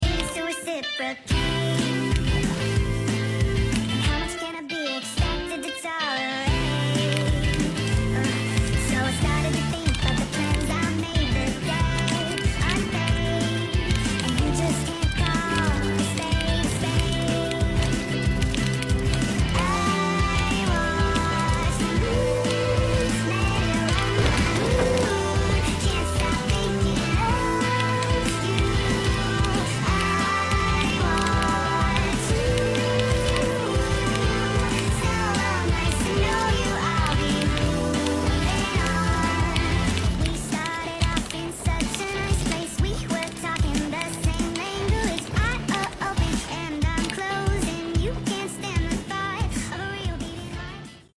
Keybord asmr fortnight#i watch the sound effects free download